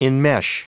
Prononciation du mot enmesh en anglais (fichier audio)
Prononciation du mot : enmesh